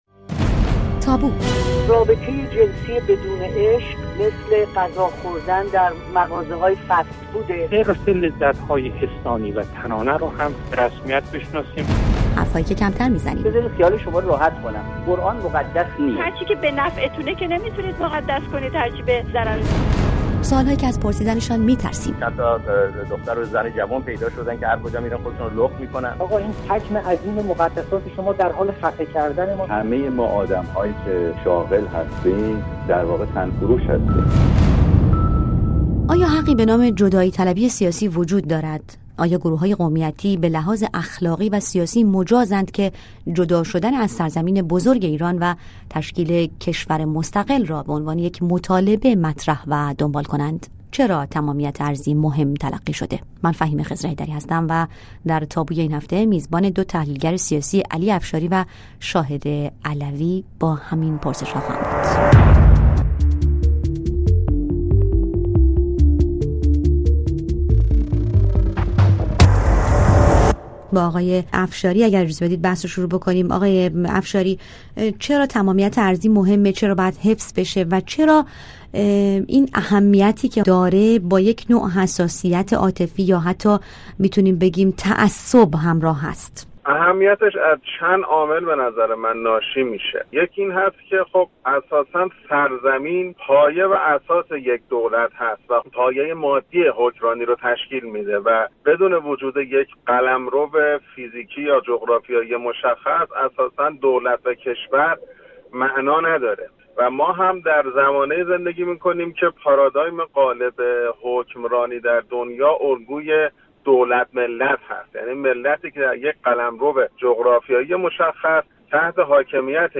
میزبان دو تحلیل‌گر سیاسی